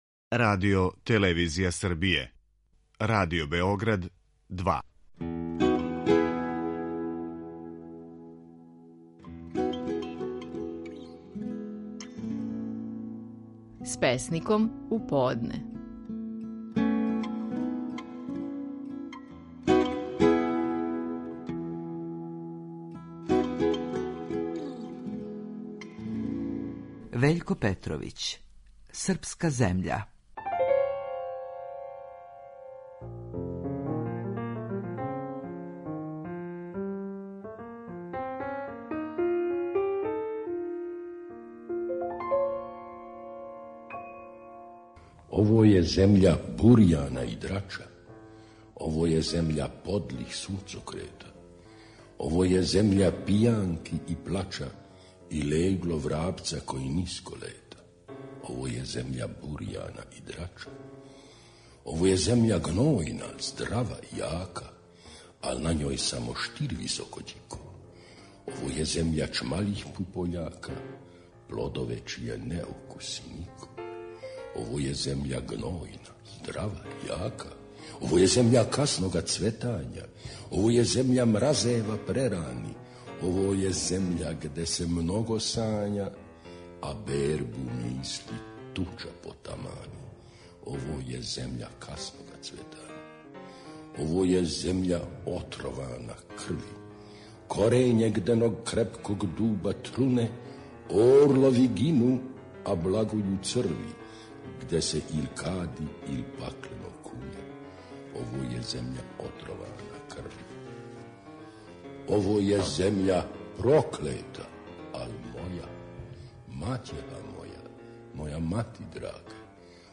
Стихови наших најпознатијих песника, у интерпретацији аутора.
Вељко Петровић говори своју песму „Српска земља".